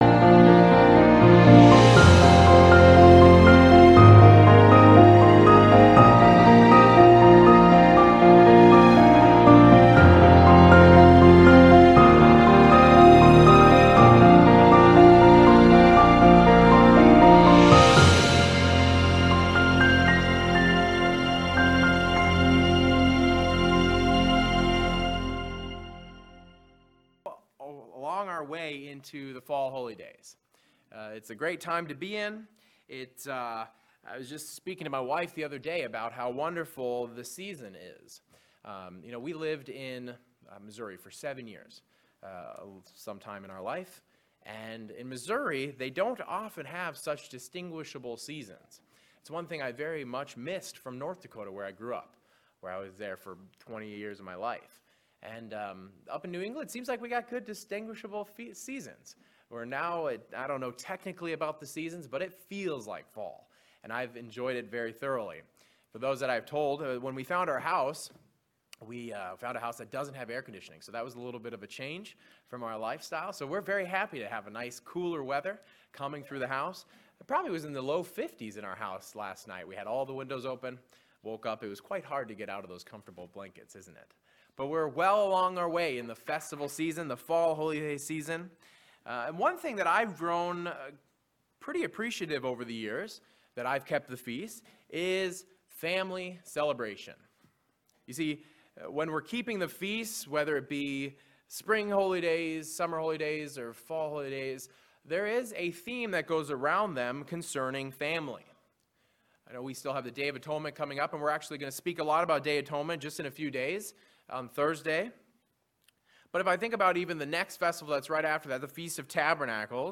Children’s Bible lessons often cover the topic of creation, but have we taken a critical look at what it means to be a creator, and what we know about how creation was made? This split sermon looks at Who, What, when, how and why related to creation.